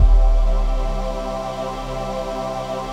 VOICEPAD07-LR.wav